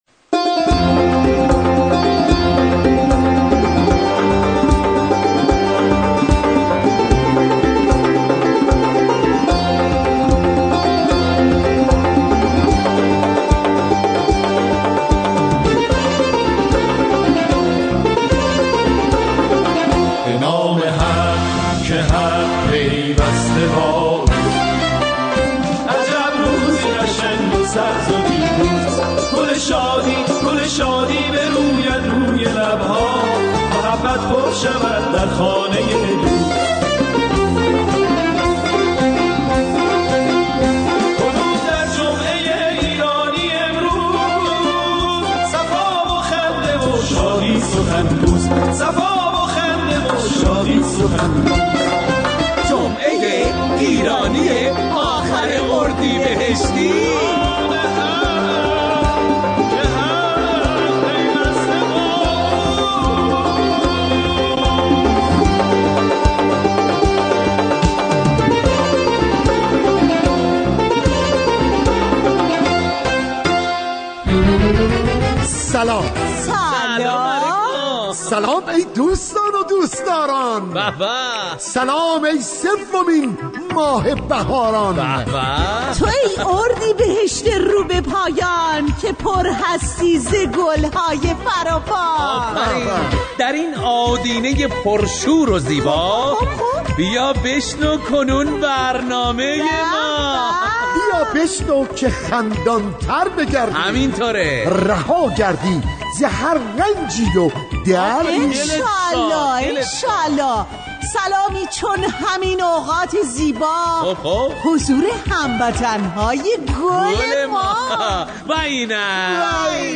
موزیک های شاد
برنامه طنز رادیو ایران